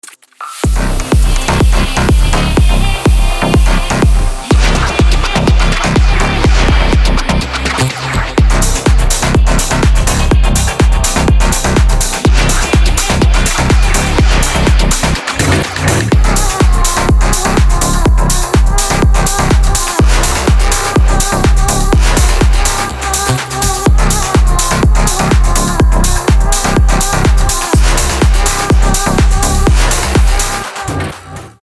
EDM
Tech House
psy-trance
индийские
транс